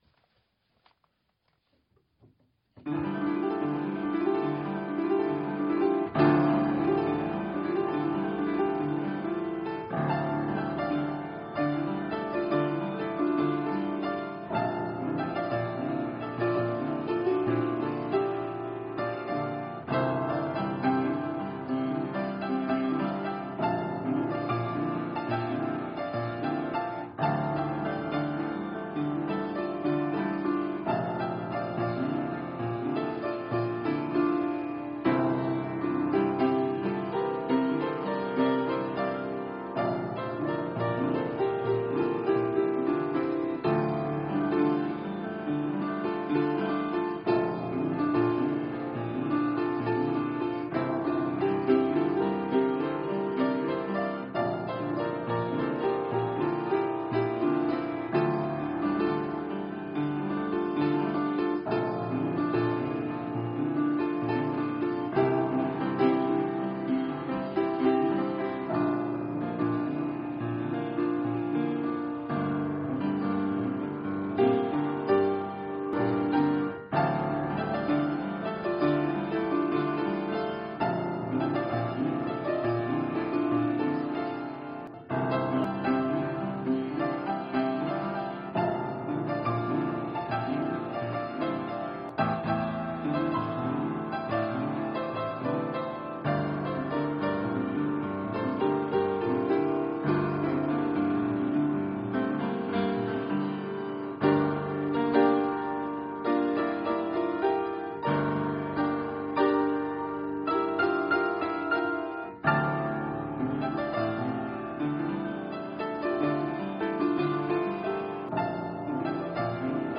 voor twee violen en piano (evt.: vl+cello+pf)
Provisorische pianoversie/provisional piano version
Het stormachtige werk [het 'wilde' trio] is in dezelfde stijl, en met dezelfde technieken gecomponeerd als 'Trio appassionato',
D klein.
Alleen is hier het tempo langzamer en de beweging en het ritme van de melodie anders.